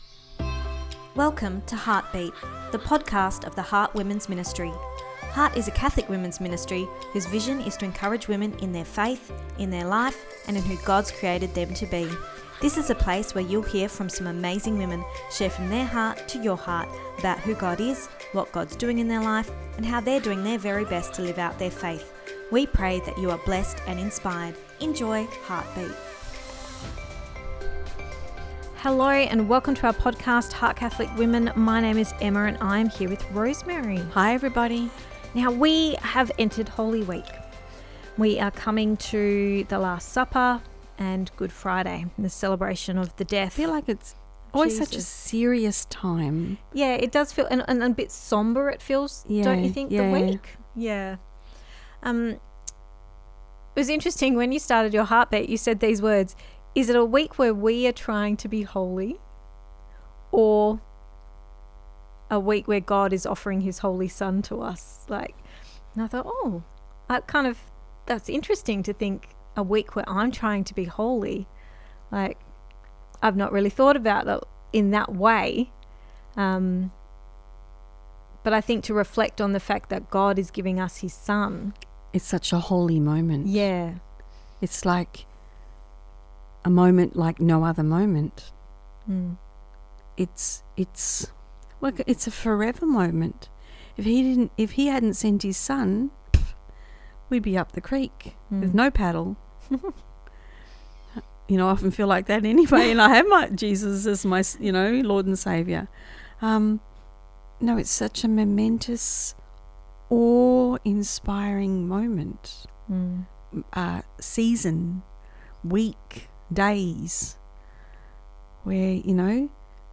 Ep291 Pt2 (Our Chat) – Reflecting on Holy Week